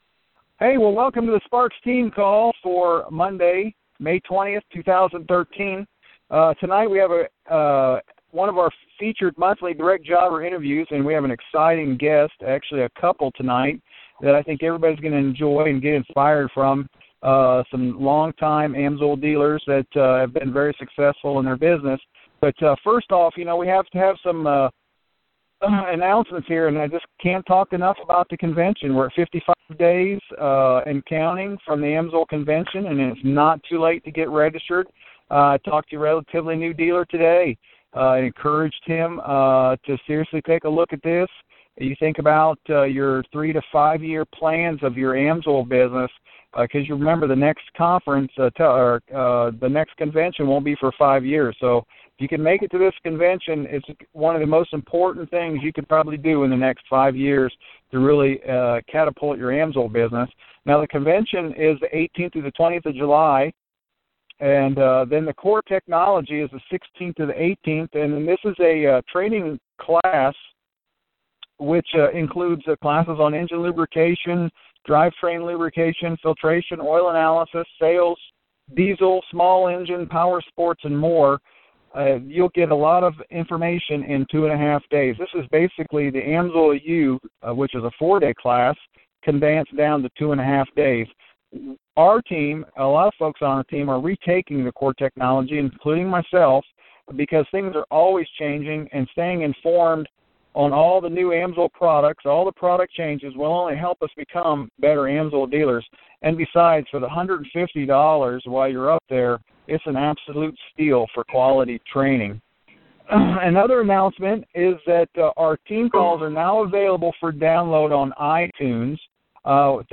Sparks Team AMSOIL Training Call for Monday May 20th, 2013.
Each month we interview an AMSOIL Direct Jobber in the company that has demonstrated proven success in the AMSOIL Business.